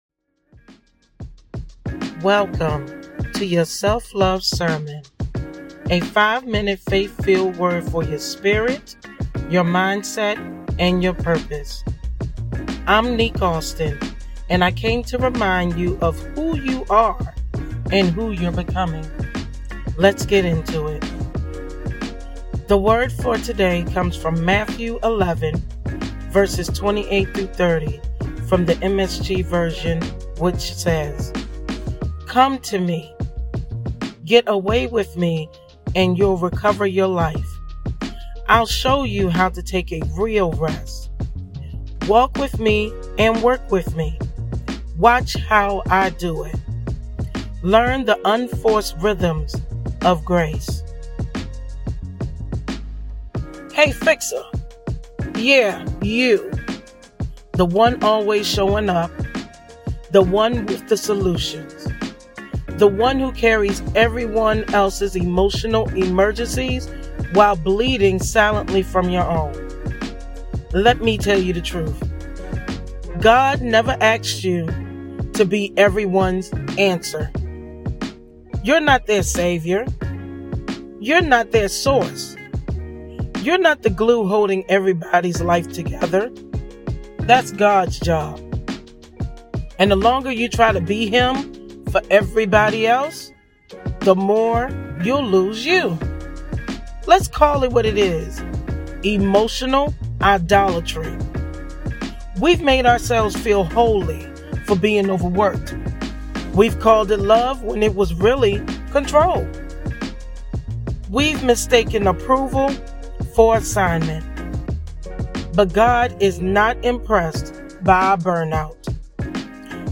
If you're navigating healing, boundaries, burnout, or bold faith moves—these sermons are your spiritual lifeline in under 5 minutes.